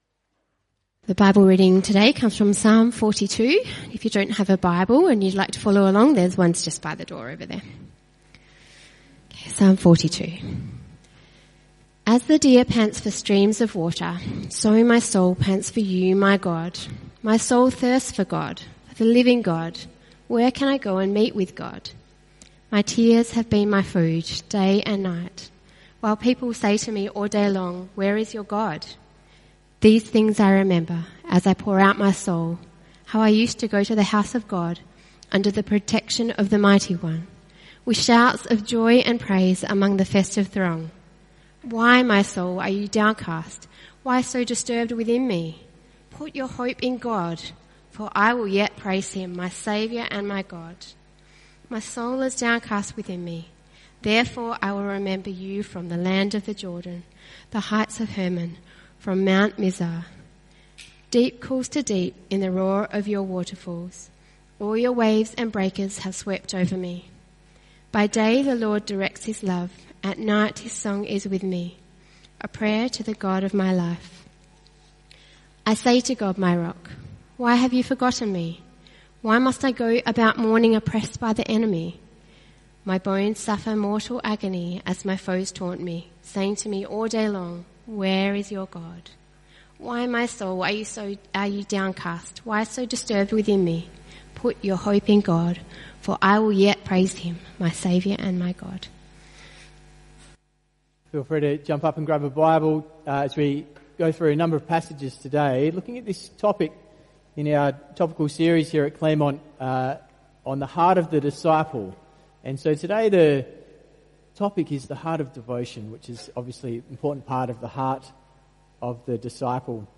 CBC Service: 24 August 2025 Series
Passage: Psalm 42, 1 Cor 15:2, 2 Cor 4:4-6, Col 2:6-7 Type: Sermons